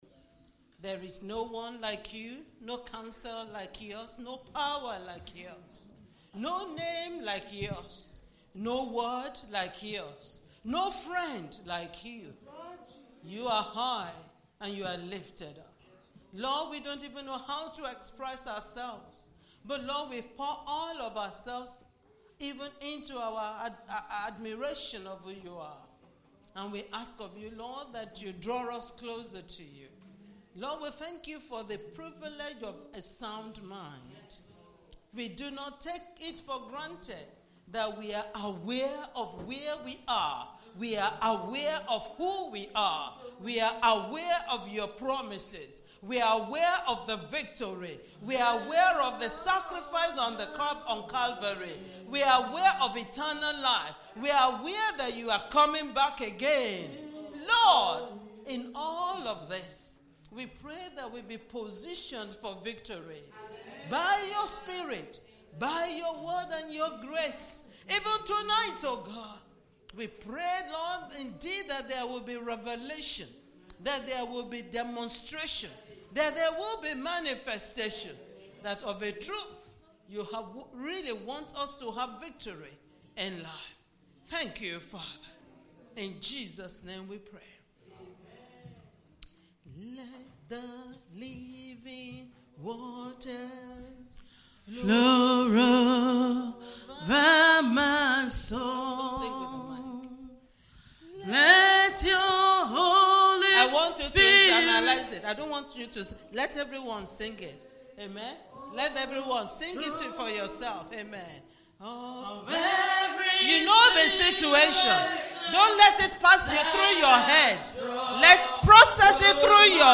PRE- ANNIVERSARY NIGHT VIGIL (21 DAYS FAST)
21-day-Fast-Pre-Anniversary-Night-Vigil-prayer-Excerpt2.mp3